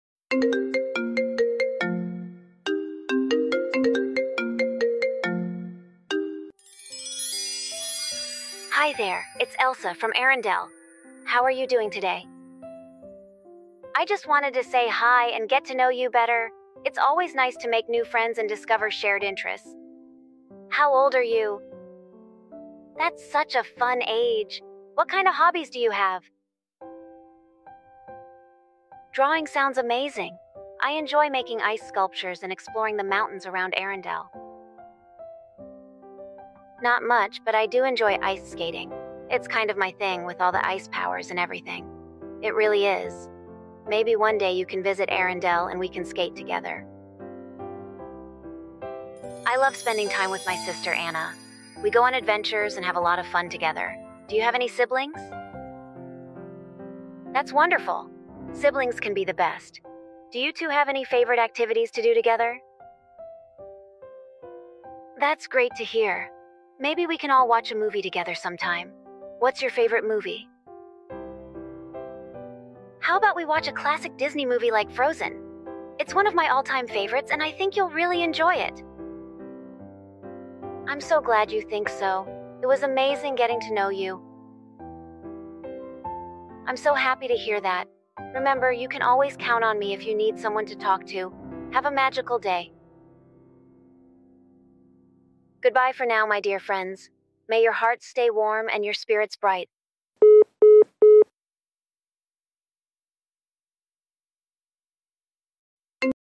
Phone call Mp3 Sound Effect Elsa is calling! Phone call with a disney princess. Fun Frozen phone call.